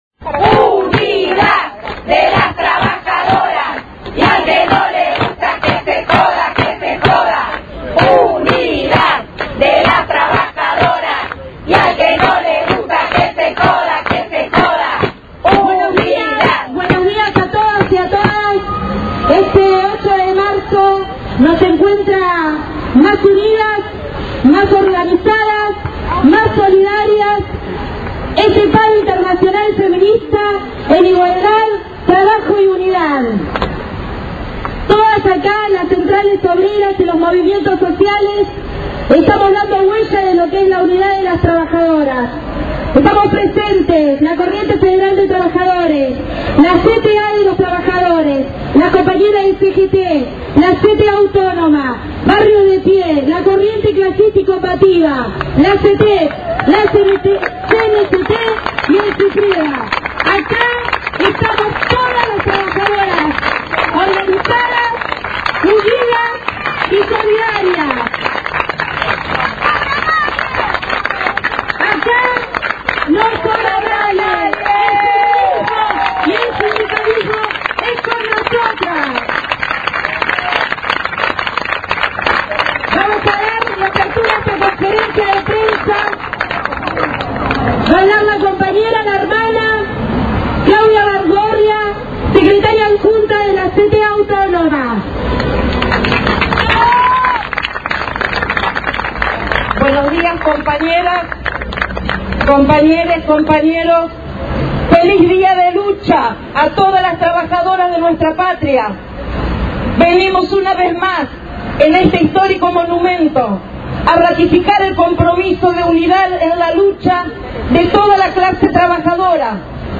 Conferencia de prensa en el Monumento "Canto al Trabajo"